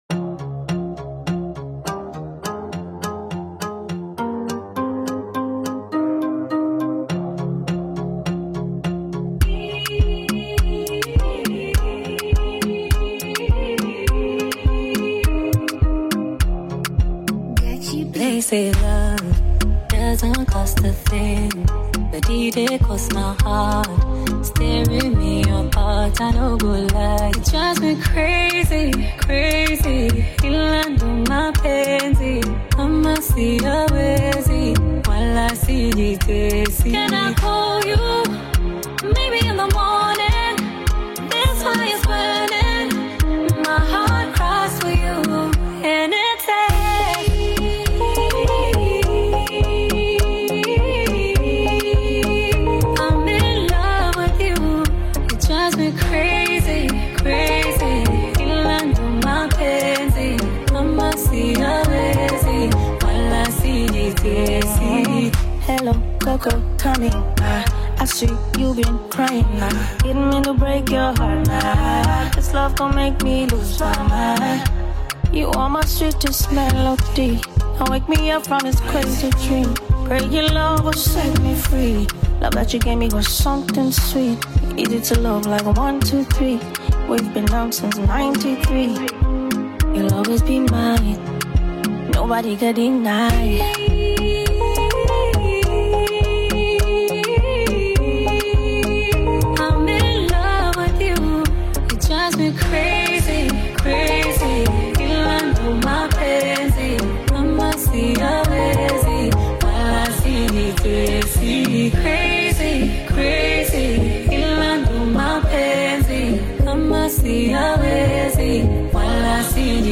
smooth Afro-pop/R&B track
soft, emotional tone that reflects longing and affection
soulful delivery